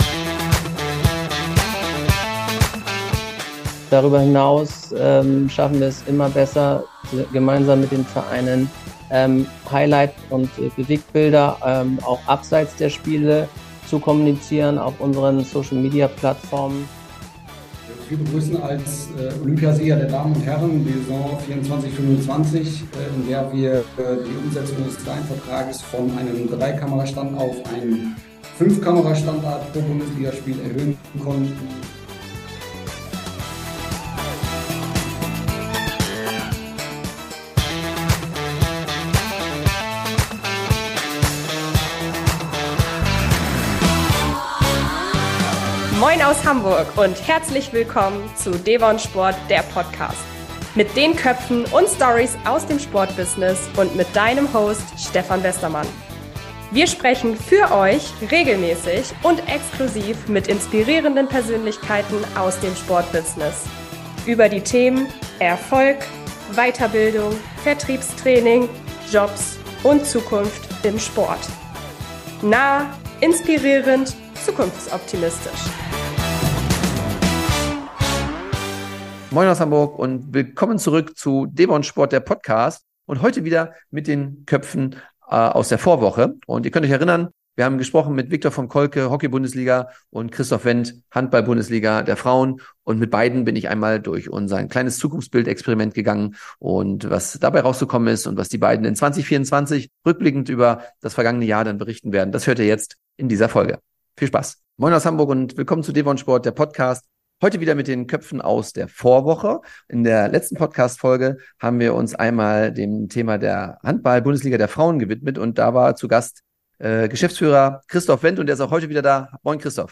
teilen heute in Teil 2 des Interviews ihre Vision für die Zukunft